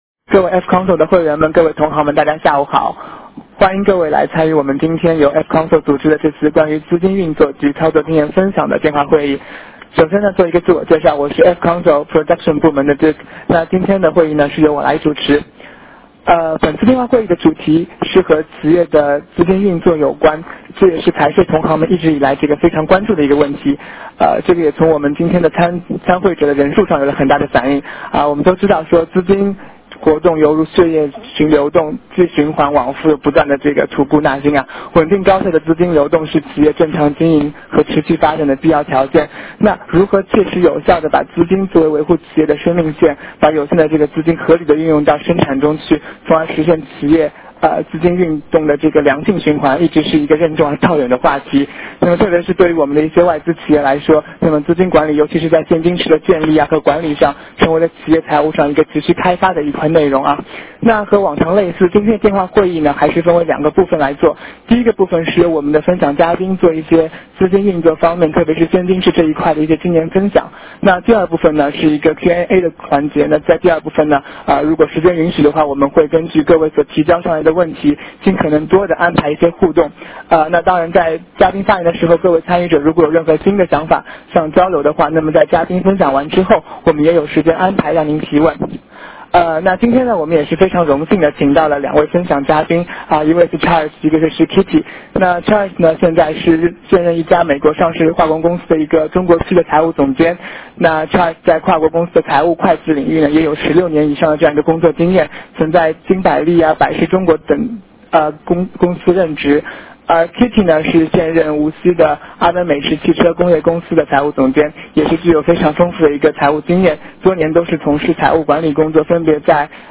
电话会议
此次电话会议我们邀请了两个特殊嘉宾做为演讲者。